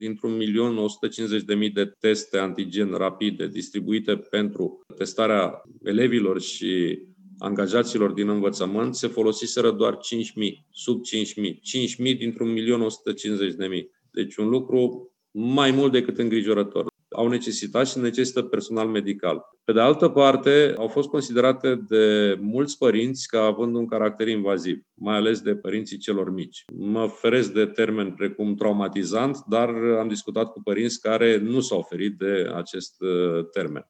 Sorin Cîmpeanu a participat joi la o dezbatere a organizației Salvați Copiii despre orele de educație remedială:
Ministrul Educației a spus că, până acum, în școli, au fost utilizate foarte puține teste rapide pentru depistarea coronavirusului: